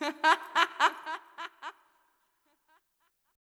Vox (Metro Laugh).wav